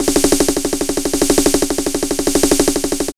81 SN FILL-R.wav